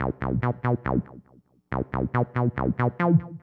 synth01.wav